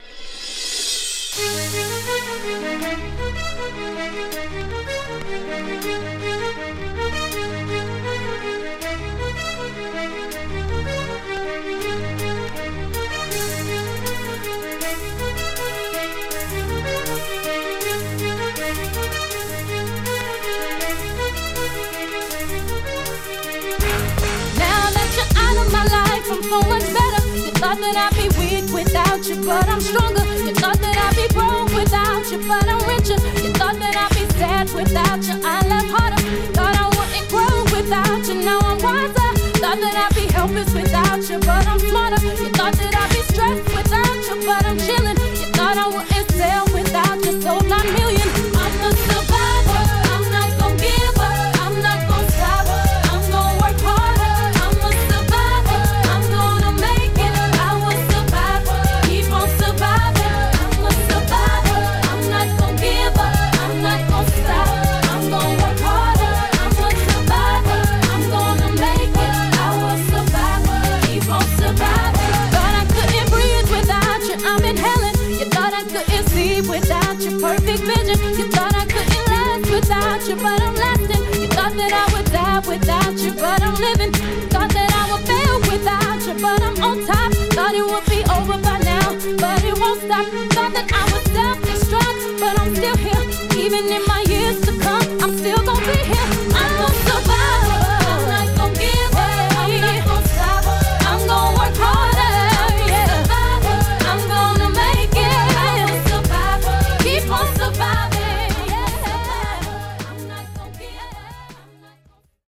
パイプオルガンの様なイントロメロディに彼女達の高貴さも感じるパワフルナンバー！